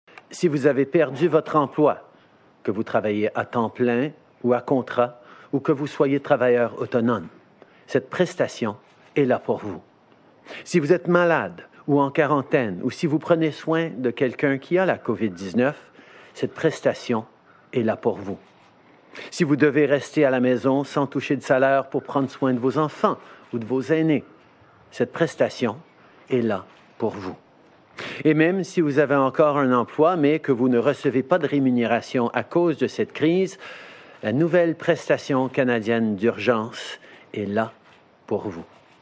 Le premier ministre Justin Trudeau a annoncé ce matin que les deux plans d’aide mis en place au début de la crise pour venir en aide aux Canadiens privés de revenu ont été fondus en un seul, la prestation canadienne d’urgence. Il explique à qui cette prestation de 2000 $ par mois s’adresse.